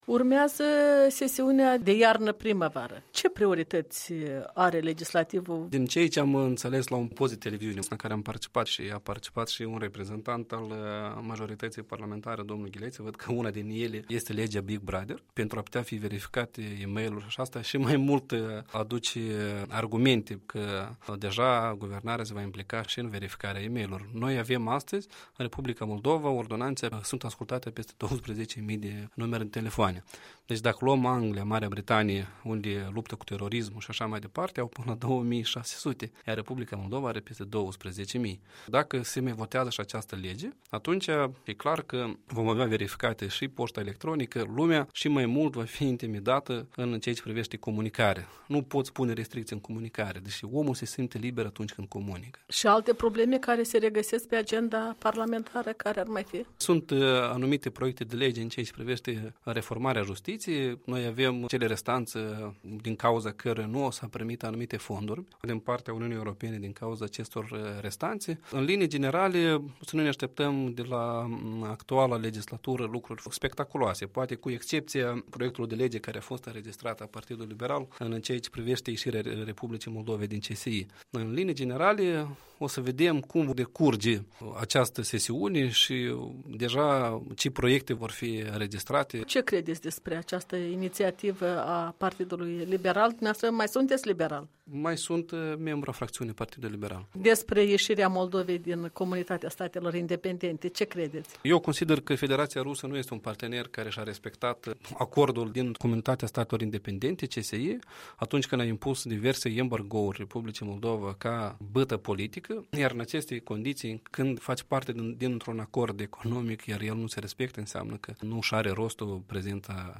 Interviu cu Lilian Carp